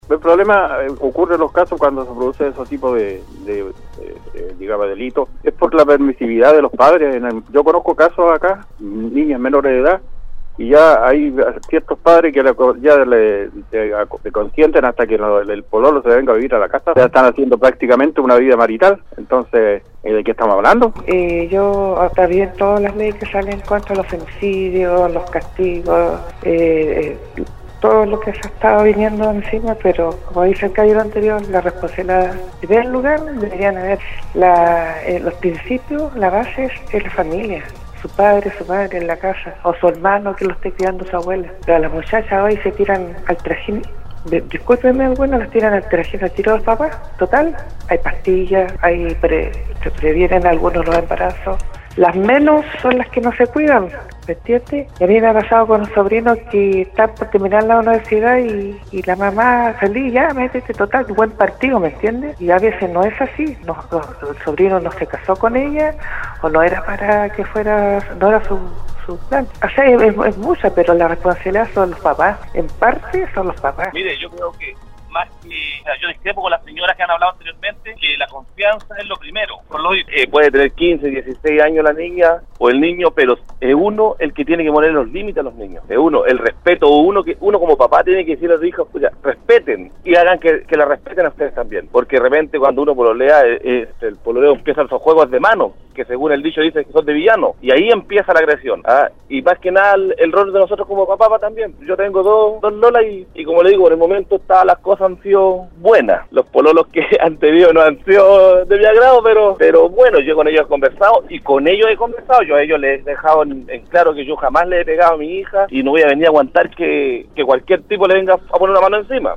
Ante la aprobación de la Cámara del Senado de la Ley Gabriela, una iniciativa que busca ampliar el concepto de femicidio con la tipificación del delito en las relaciones del noviazgo y el pololeo, auditores de Nostálgica participaron en el foro del programa Al día, donde se refirieron a cuales podrían ser las causas que han aumentado en los últimos años los casos de femicidio en el país.